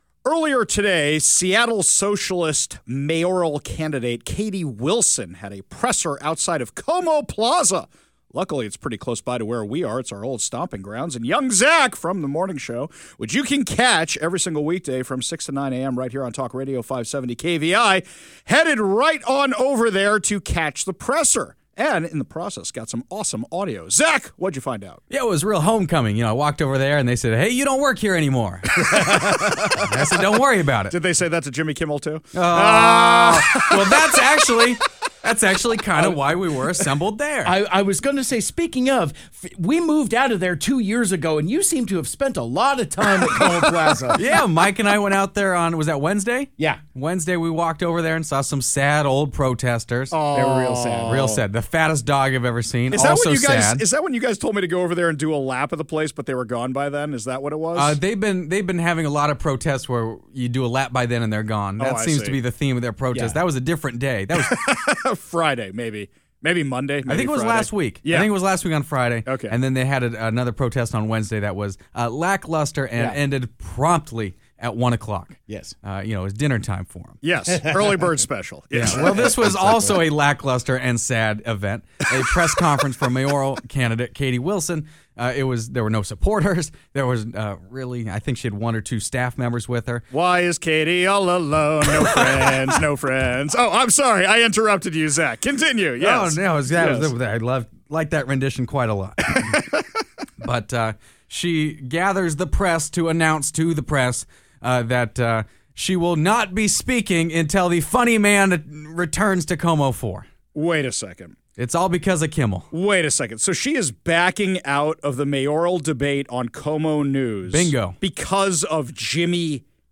A Press Conference Without Supporters